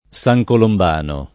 Saj kolomb#no] top.